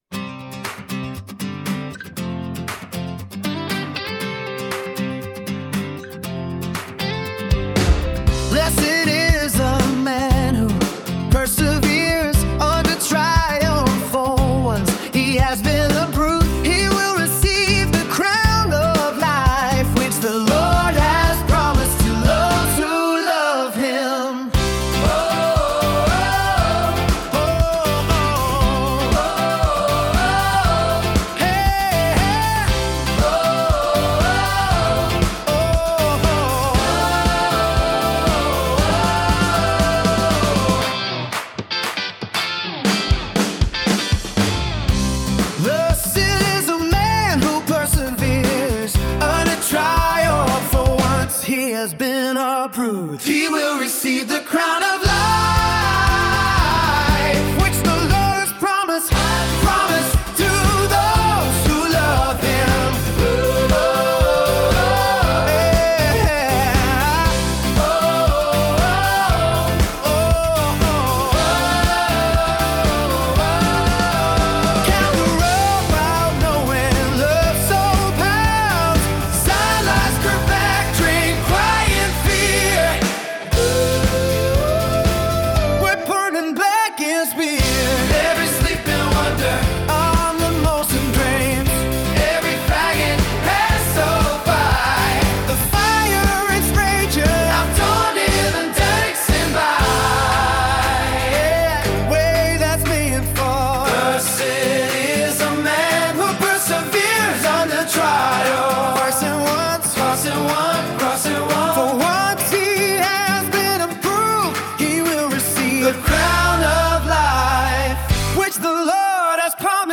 Christian worship music for strength and encouragement